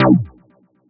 Synth Stab 12 (C).wav